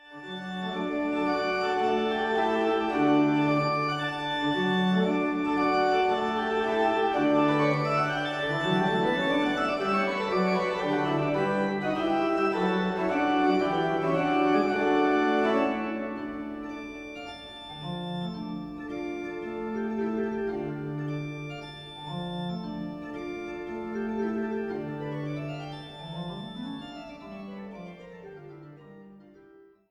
Eule-Orgel im Dom zu Zeitz
Orgel